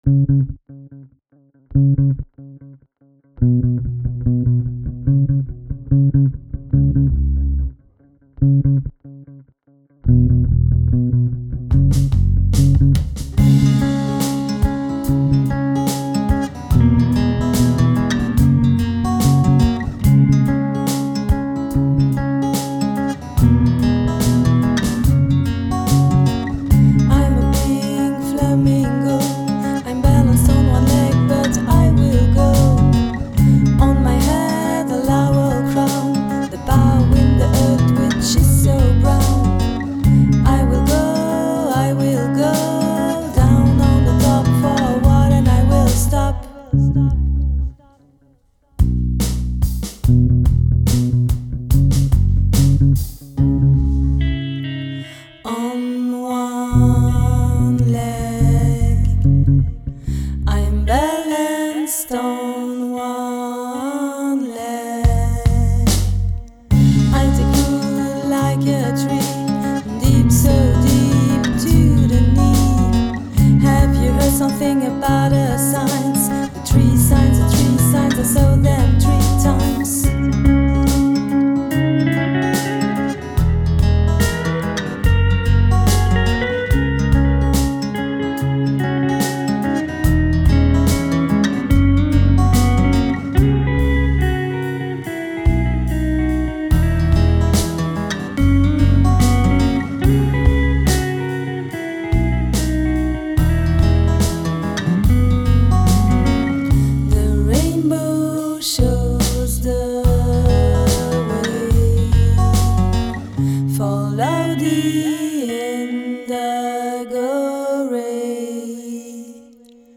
Chant
Guitares
Basse
Batterie